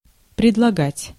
Ääntäminen
France: IPA: [pʁo.po.ze]